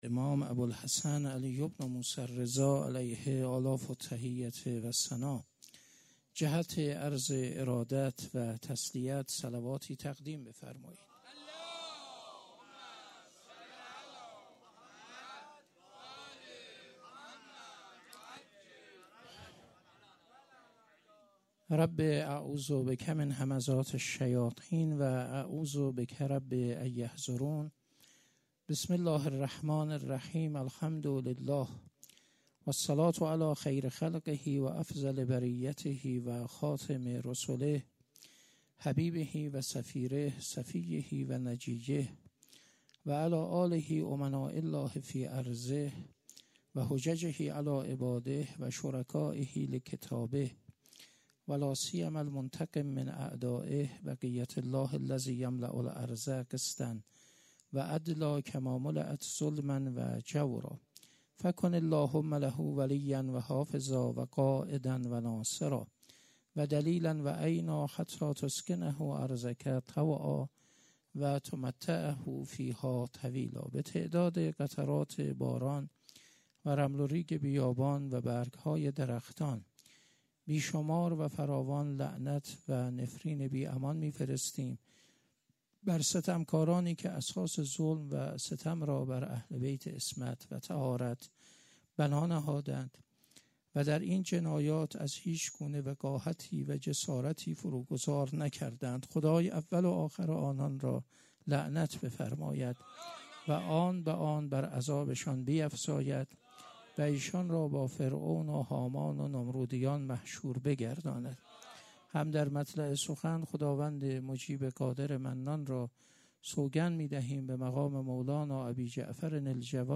20 مرداد 97 - سخنرانی